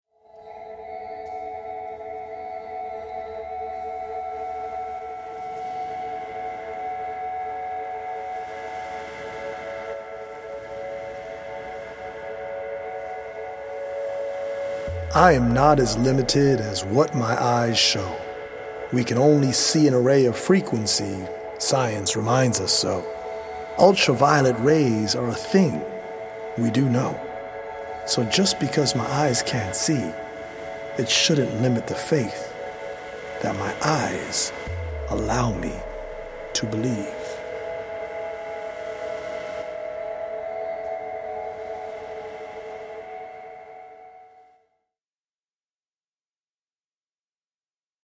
as well as healing Solfeggio frequency music by EDM producer